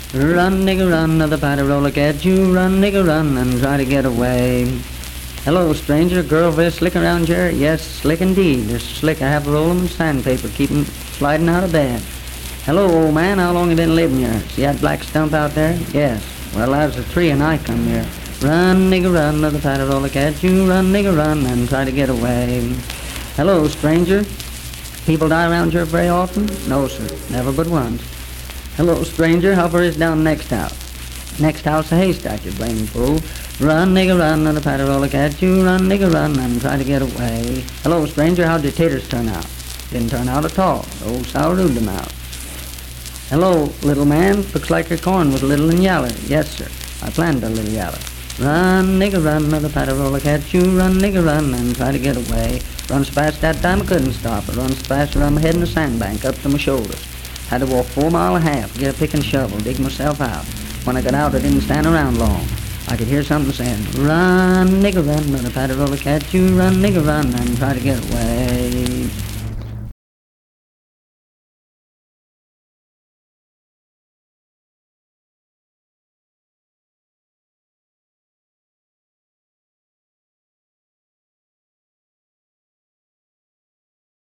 Unaccompanied vocal music performance
Dance, Game, and Party Songs, Folklore--Non Musical, Minstrel, Blackface, and African-American Songs, Humor and Nonsense
Voice (sung)